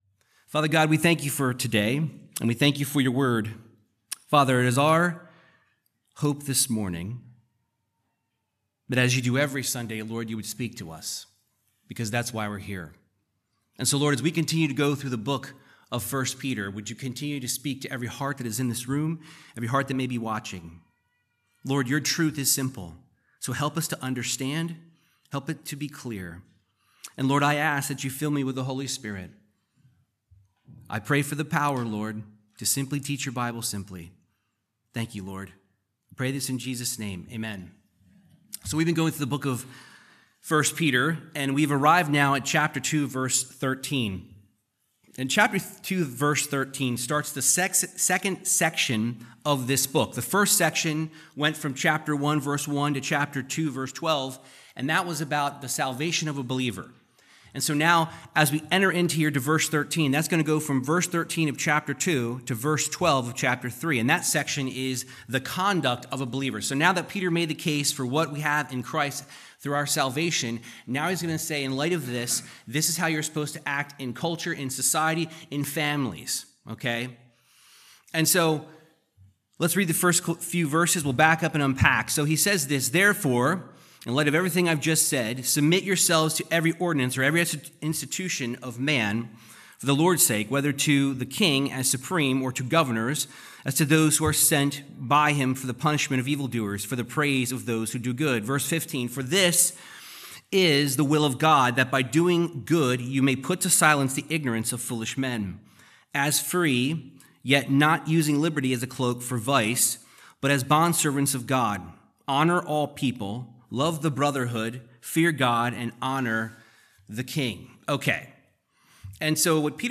Verse by verse Bible teaching on 1 Peter 2:13-25 discussing the Christian's life under those in authority.